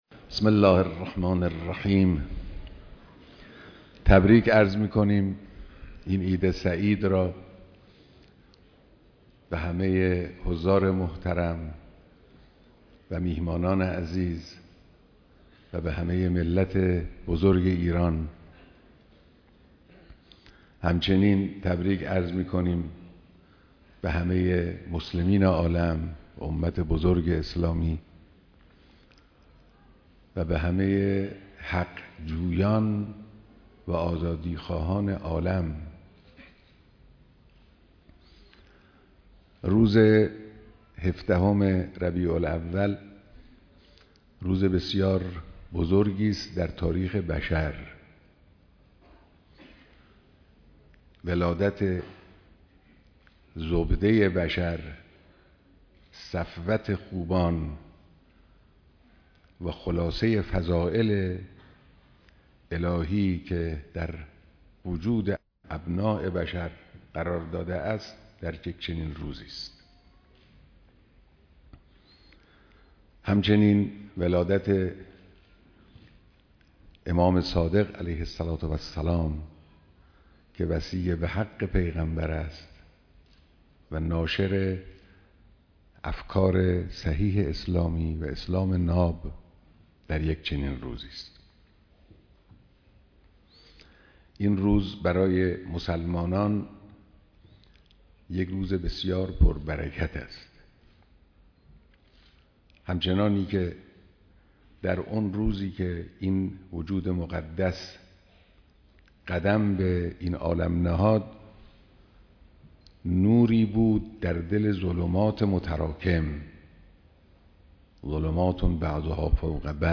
دیدار مسئولان نظام و قشرهای مختلف مردم